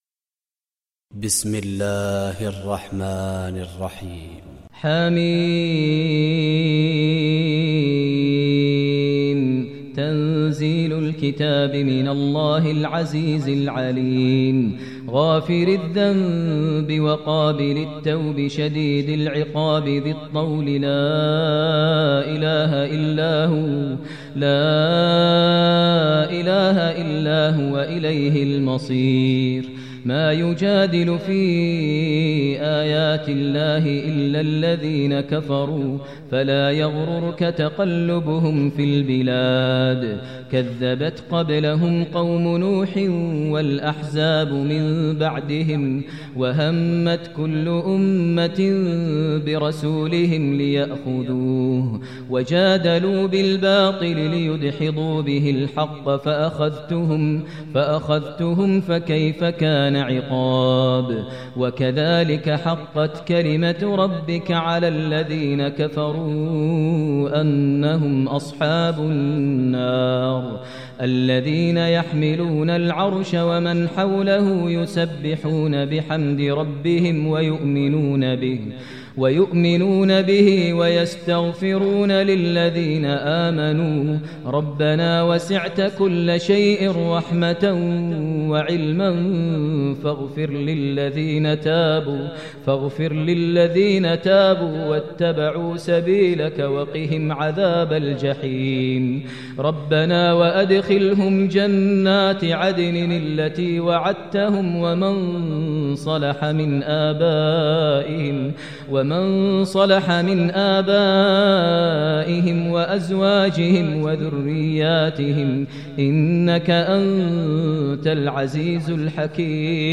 Listen online and download beautiful Tilawat / Recitation of Surah Al Ghafir in the voice of Sheikh Maher al Mueaqly.